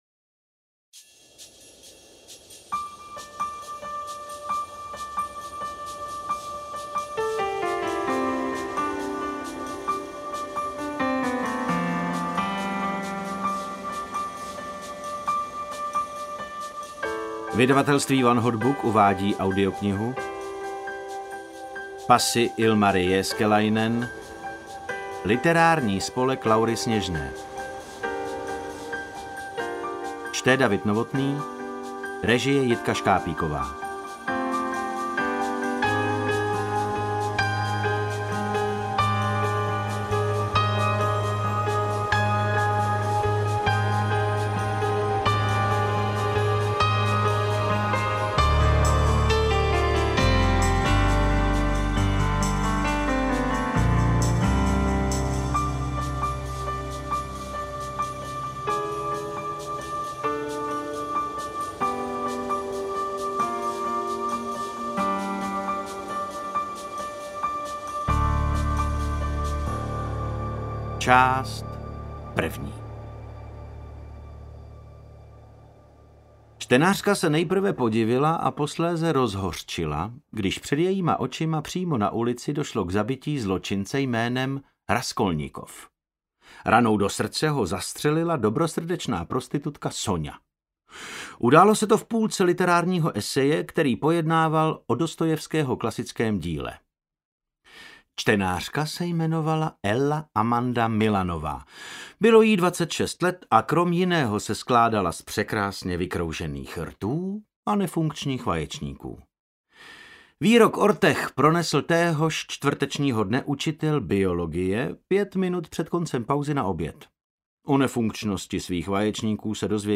Interpret:  David Novotný
Divadelní a filmový herec, dabér a vynikající interpret audioknih.